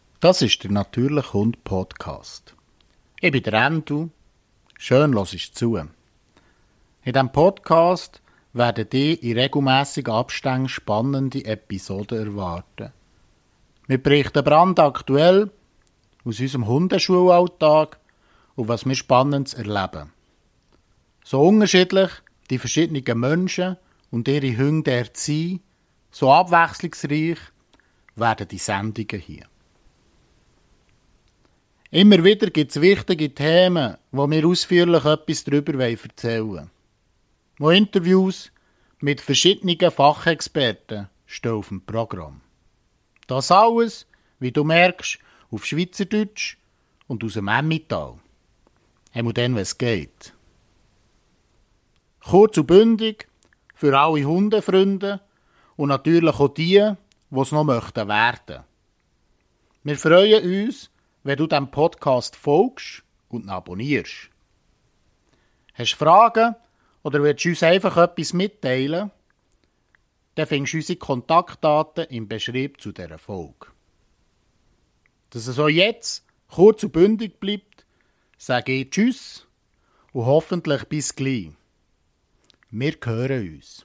Alles brandaktuell, in Dialekt